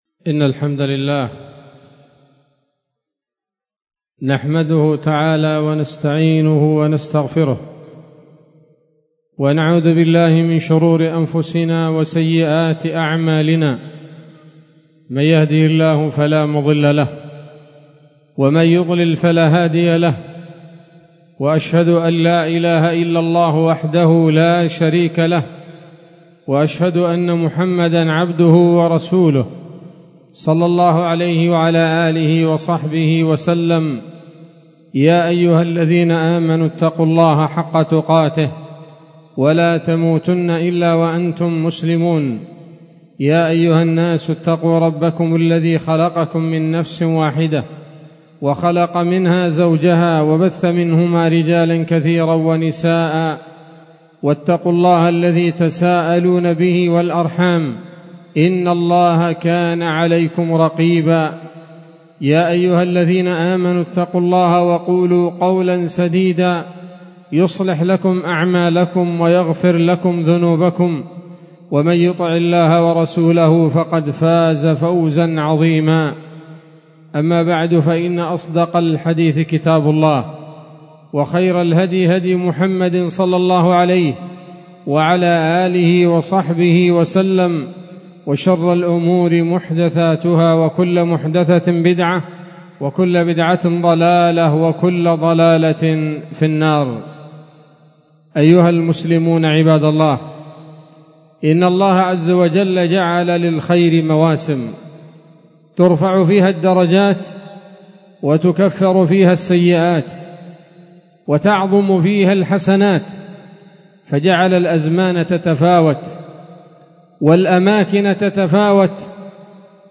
خطبة جمعة بعنوان: (( أفضل الأيام عند الله )) 1 ذي الحجة 1445 هـ، دار الحديث السلفية بصلاح الدين